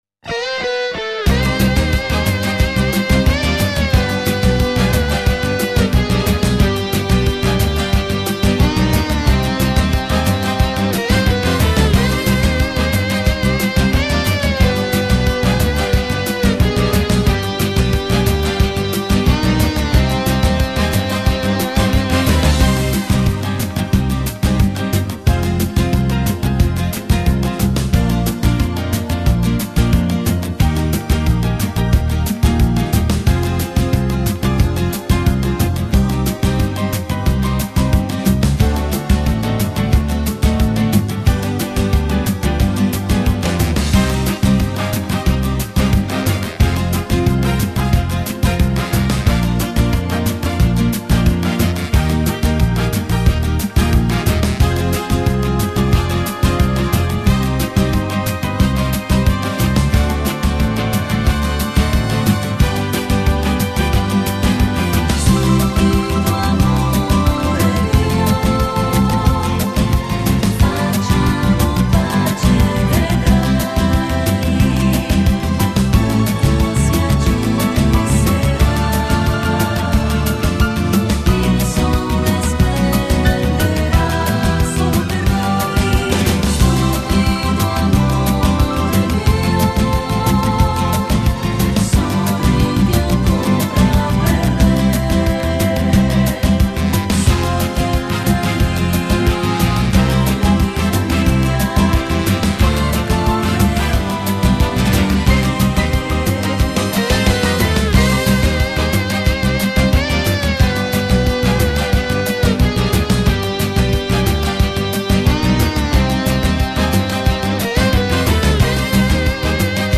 Genere: Rumba rock
Scarica la Base Mp3 (3,49 MB)